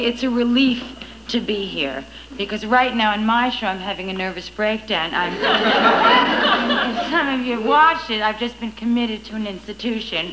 In addition, I am pleased to be able to include several files recorded from the rarely seen episode of "Saturday Night Live" which Louise Lasser hosted on July 24, 1976.  The show featured a bizarre monologue, somewhat like the monologues offered by other hosts.